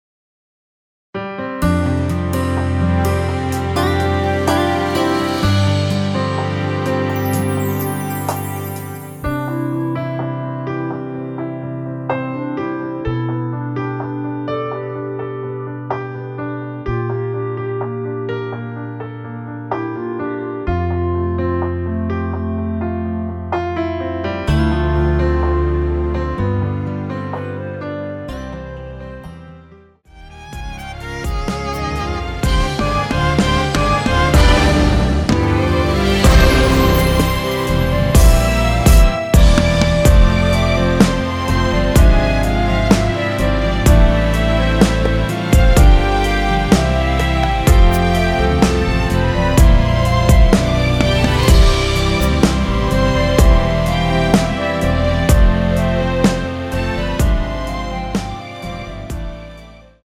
원키에서(-3)내린 멜로디 포함된 MR입니다.
앞부분30초, 뒷부분30초씩 편집해서 올려 드리고 있습니다.